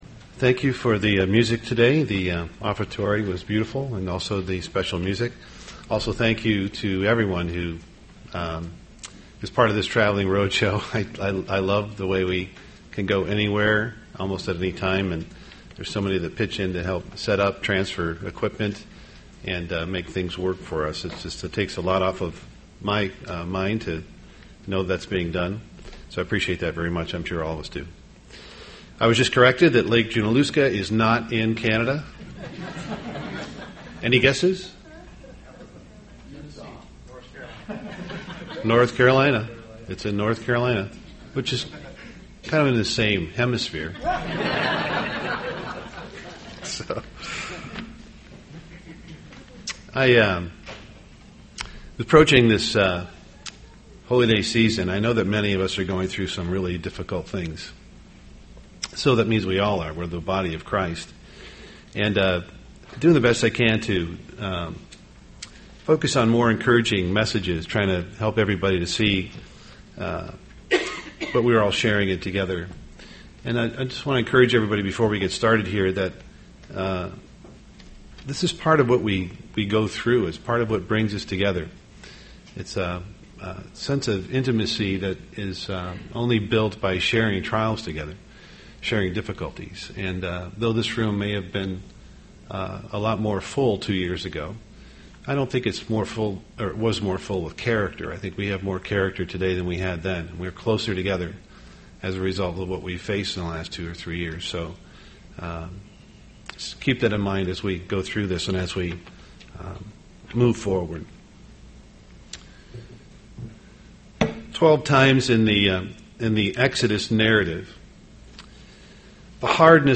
UCG Sermon Unleavened Bread hard heart voice of God Studying the bible?